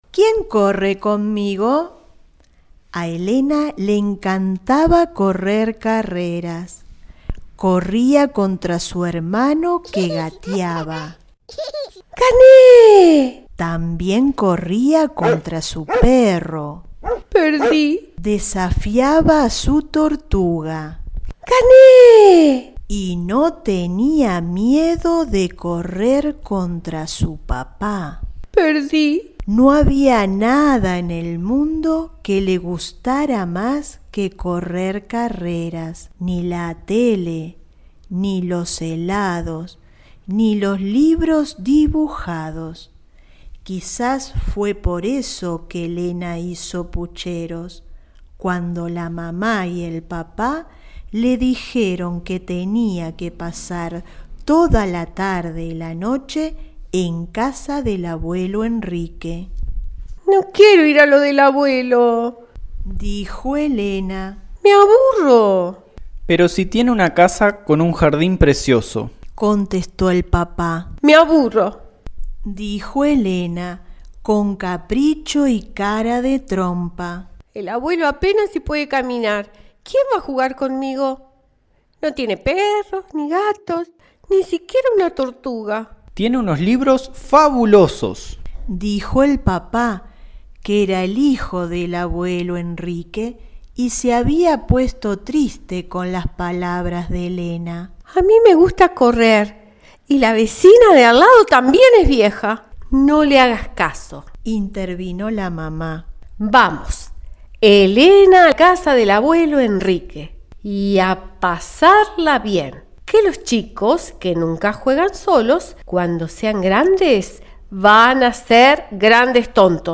[Audiotexto].